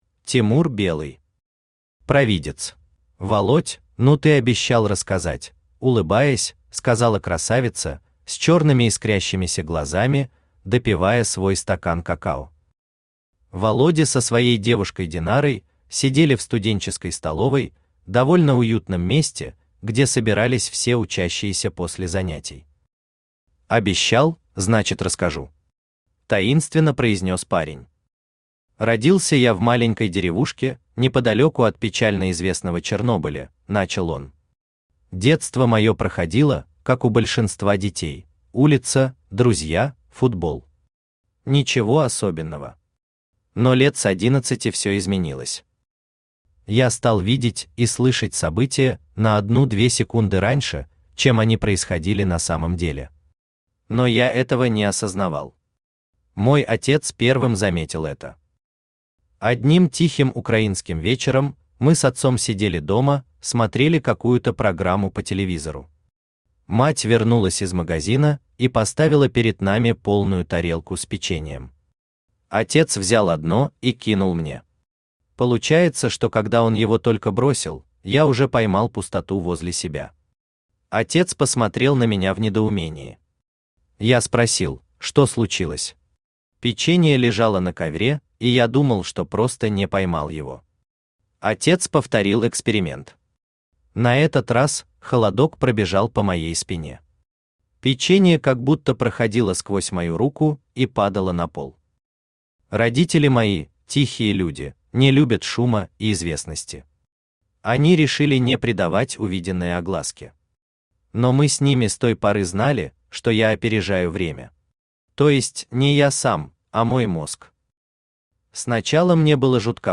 Аудиокнига Провидец | Библиотека аудиокниг
Aудиокнига Провидец Автор Тимур Белый Читает аудиокнигу Авточтец ЛитРес.